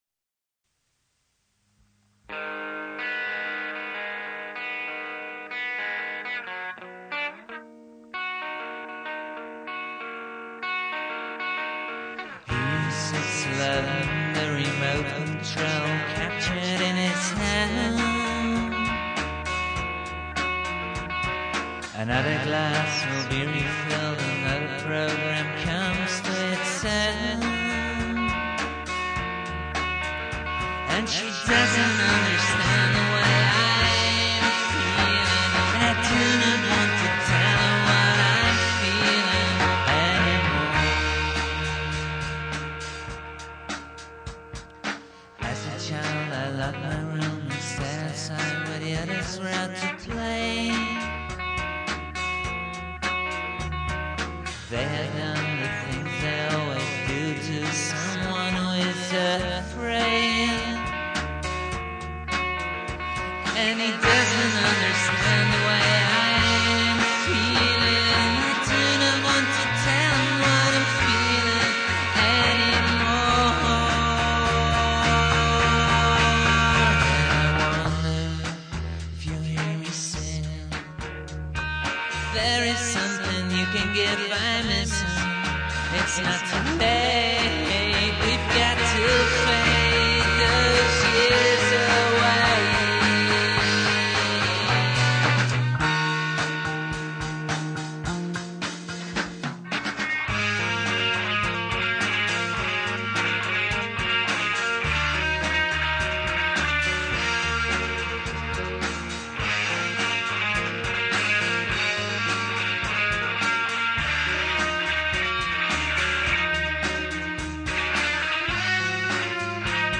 where: recorded at AMP (Amsterdam)
trivia: very dramatic.